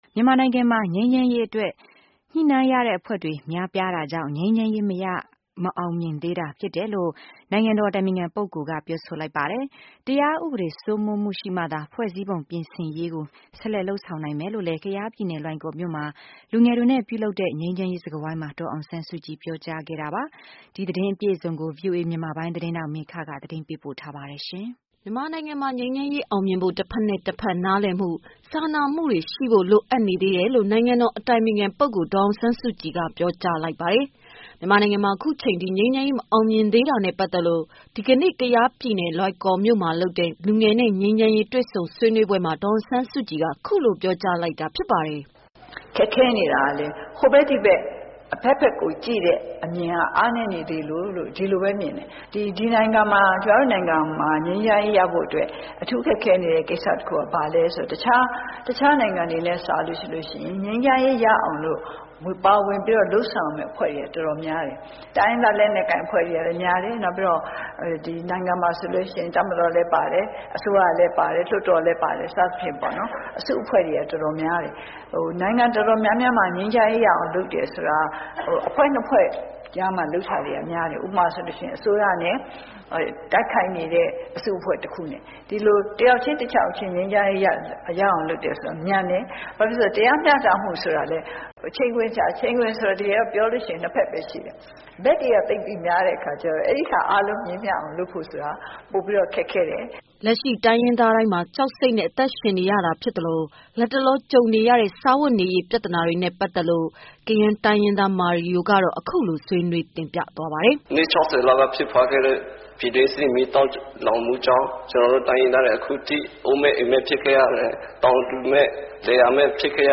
မြန်မာနိုင်ငံမှာ ငြိမ်းချမ်းရေးအတွက် ညှိနှိုင်းရတဲ့အဖွဲ့တွေ များပြားတာကြောင့် ငြိမ်းချမ်းရေး မအောင်မြင်သေးတာဖြစ်တယ်လို့ နိုင်ငံ တော်အတိုင်ပင်ခံ ပုဂ္ဂိုလ်က ပြောဆိုလိုက်ပါတယ်။ တရားဥပဒေစိုးမိုးမှုရှိမှသာ ဖွဲ့စည်းပုံပြင်ဆင်ရေးကိုဆက်လက်လုပ်ဆောင် နိုင်မယ်လို့လည်း ကယားပြည်နယ် လွိုင်ကော်မြို့မှာ လူငယ်တွေနဲ့ပြုလုပ်တဲ့ ငြိမ်းချမ်းရေးစကားဝိုင်းမှာ ဒေါ်အောင်ဆန်းစုကြည်က ပြောကြားခဲ့ပါတယ်။